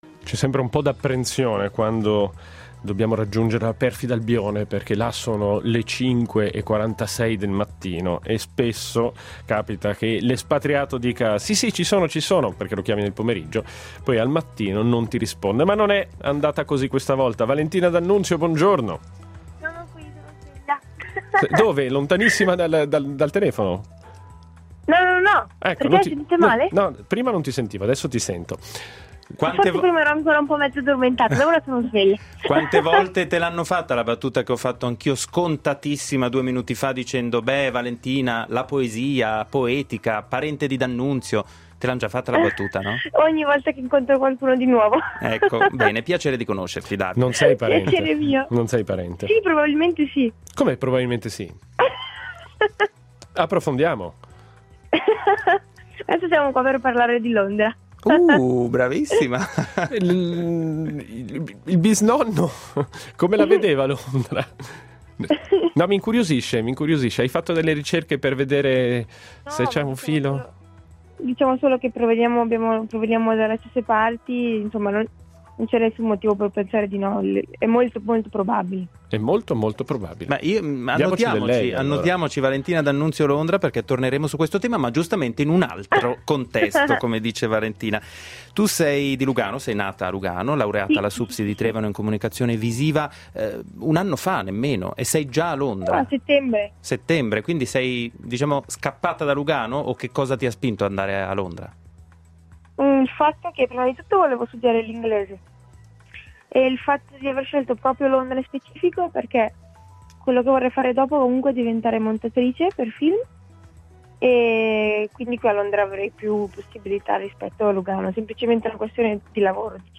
Oltre ad ascoltare l’intervista, questo è il suo sito e questa la sua pagina instagram.